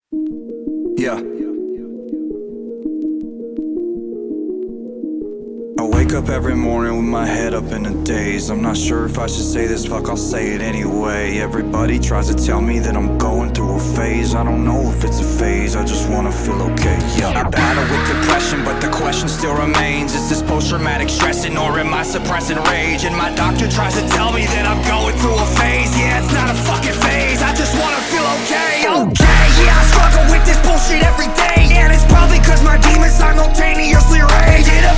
Жанр: Рок / Пост-хардкор / Хард-рок